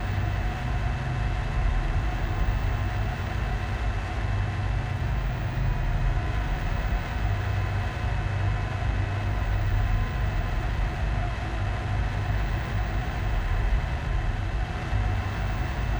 pgs/Assets/Audio/Sci-Fi Sounds/Mechanical/Engine 7 Loop.wav at master
Engine 7 Loop.wav